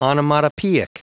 Prononciation du mot onomatopoeic en anglais (fichier audio)
Prononciation du mot : onomatopoeic